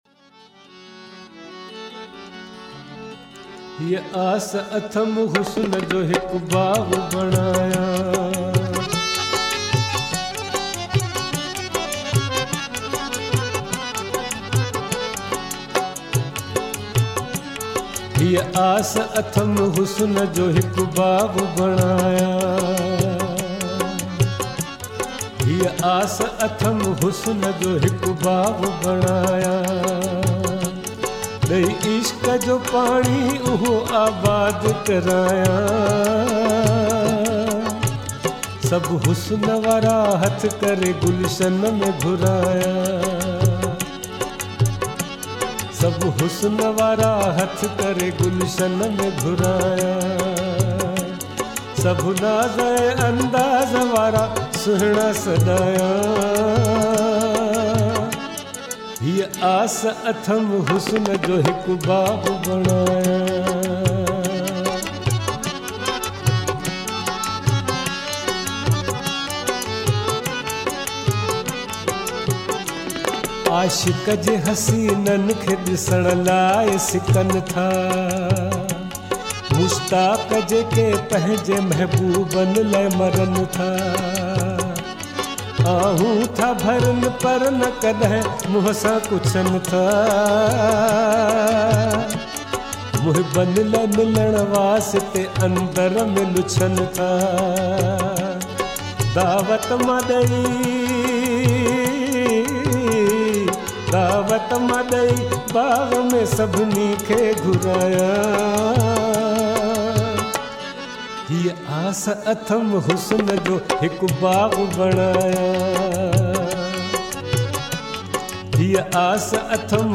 in soothing voice